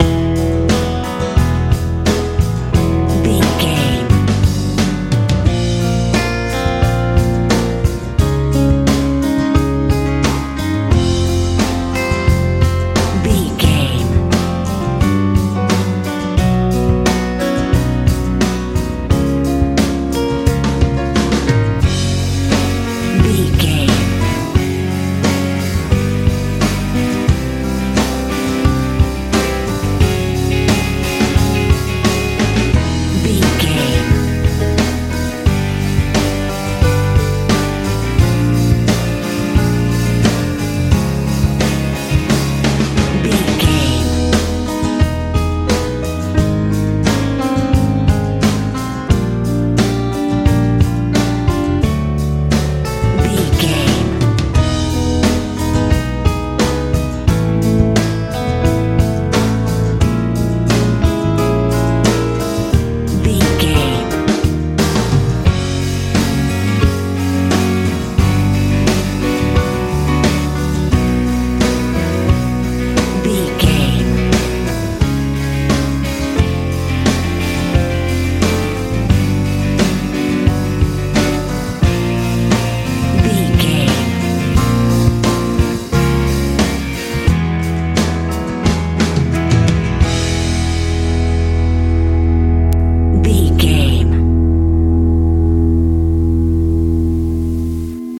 folk rock feel
Ionian/Major
hopeful
piano
acoustic guitar
drums
bass guitar